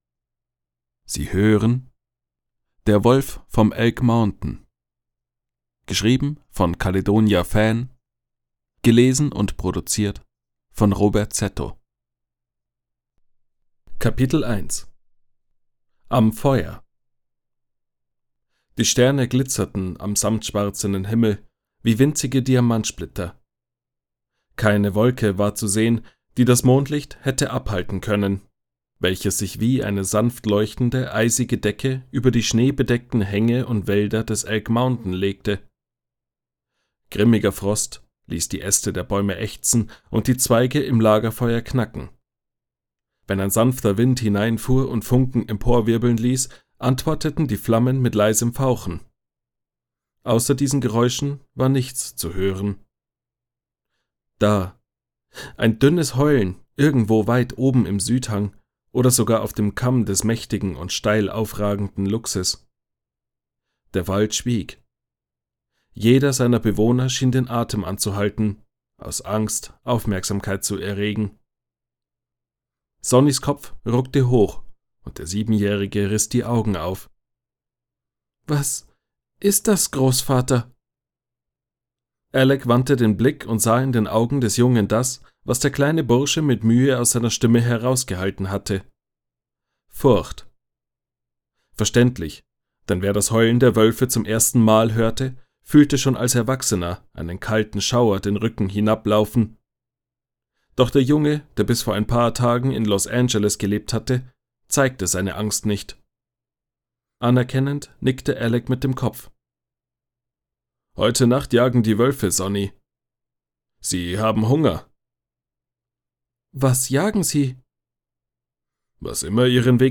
Hörbuch | Familien & Drama